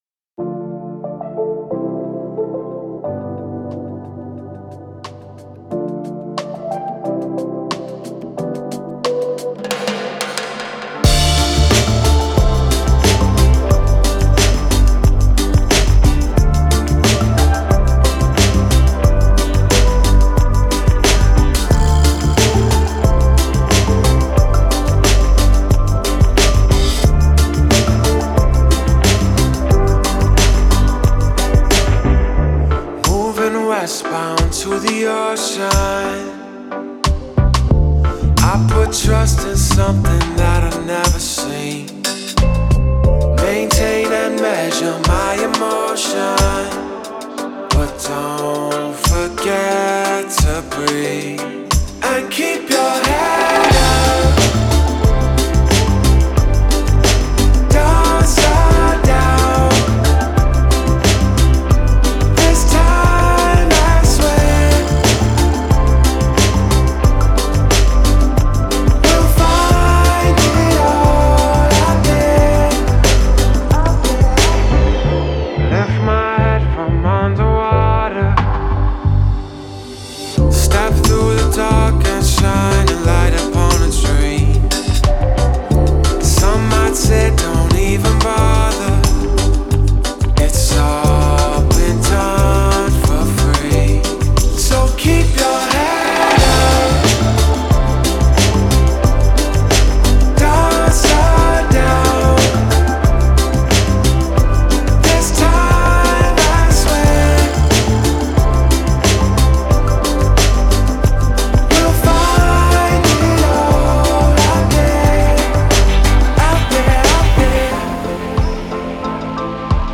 blending alternative and reggae-rock influences.